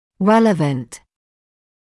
[‘reləvənt][‘рэлэвэнт]релевантный; относящийся к (ч.-либо); уместный